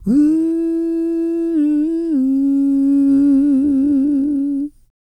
E-CROON P328.wav